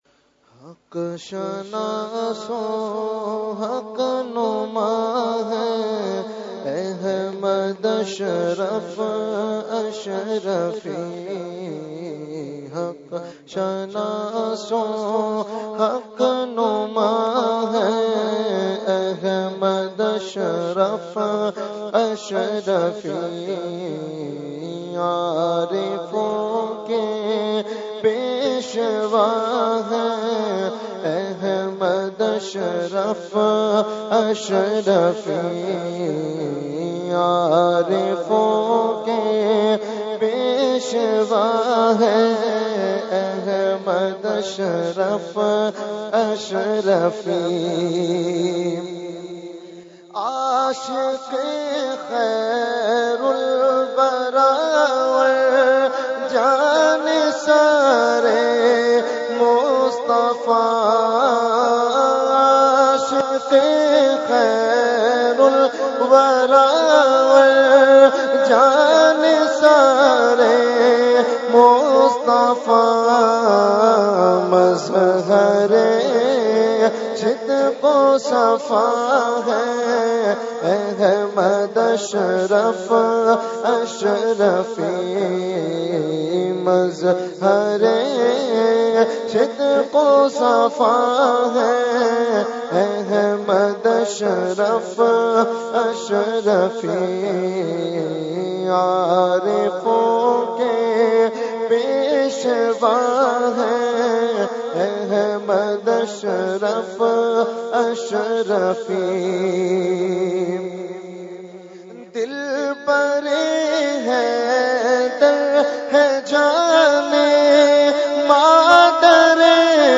Category : Manqabat | Language : UrduEvent : Urs Qutbe Rabbani 2019